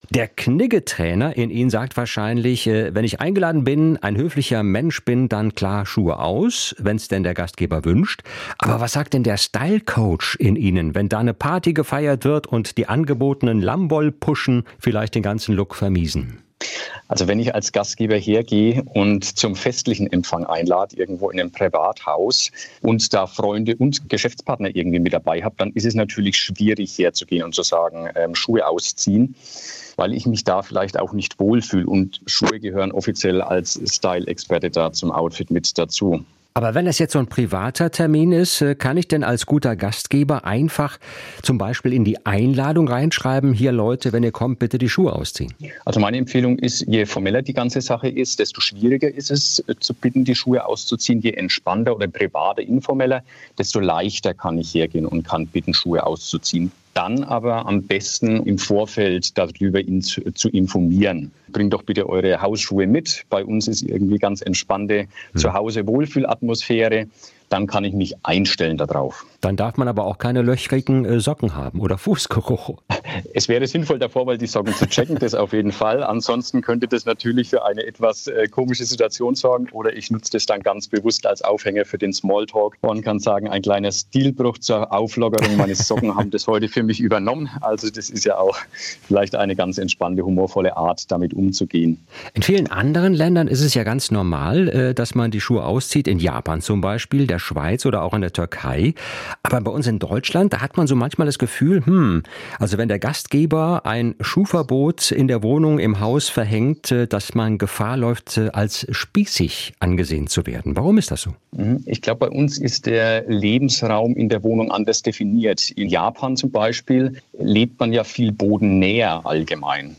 SWR1 Interviews
Interview mit